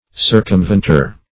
Search Result for " circumventor" : The Collaborative International Dictionary of English v.0.48: Circumventor \Cir`cum*vent"or\, n. [L.]